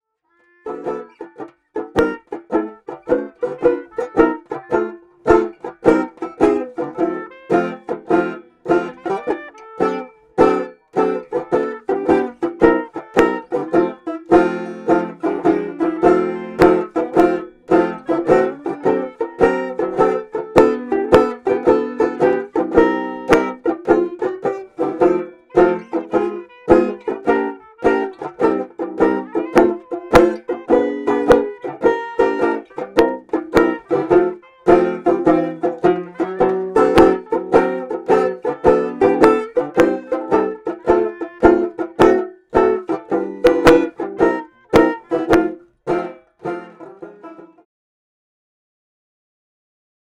Three microphones (one attached to each instrument), each connected to a portable recorder to go in the musician’s various pockets.
I haven’t yet assembled the separate recordings, but I can confirm that I managed to capture the sound of clarinet, banjo, sousaphone and hat for every single note of our walkaround sets, in crystal clear quality.
Banjo:
Friday-Set-1-Banjo.mp3